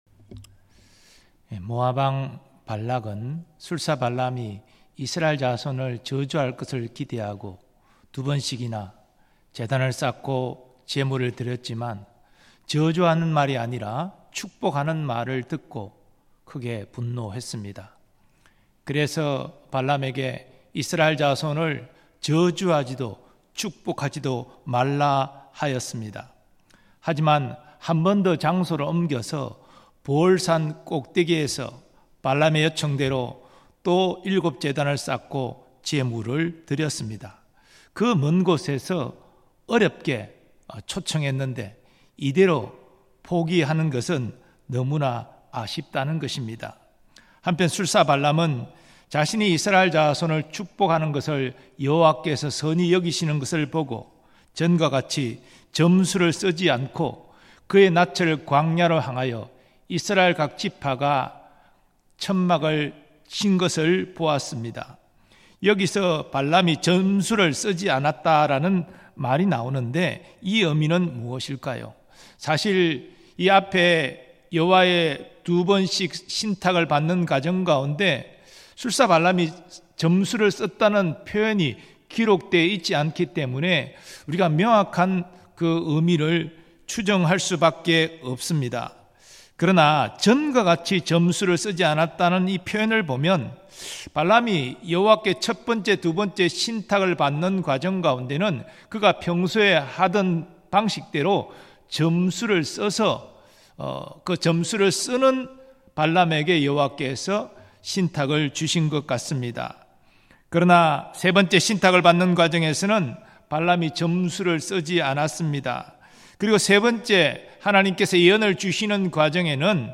2024년 7월 10일 삼일예배 말씀
음성설교